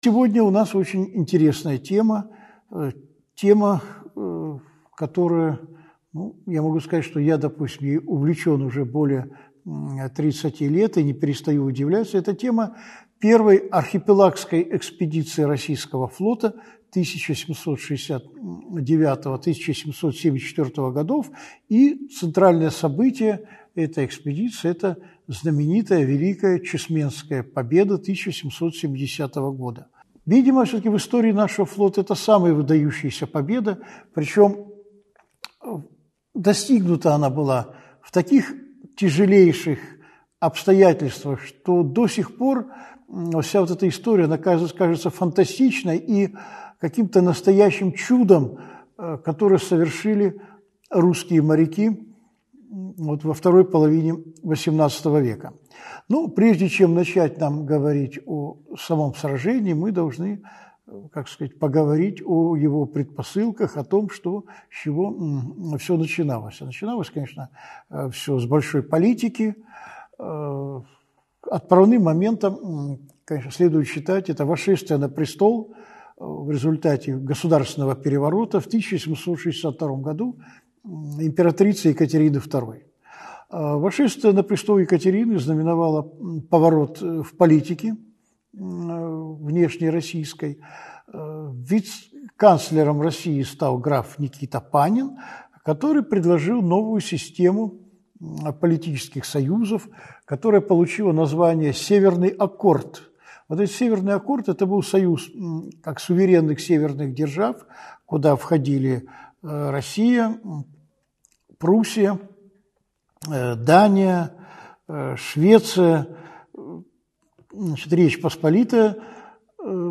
Аудиокнига Чесменская победа. Часть 1 | Библиотека аудиокниг